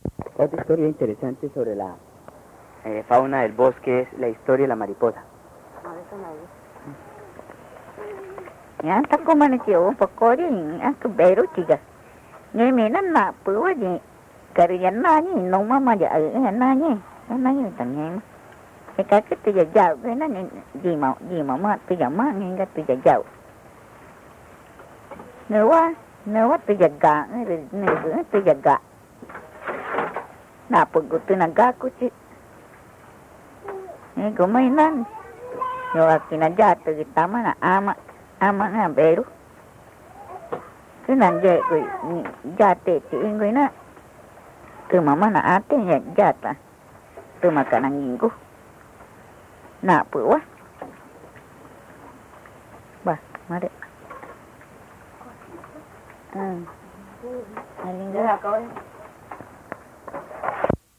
Boyahuazú, Amazonas (Colombia)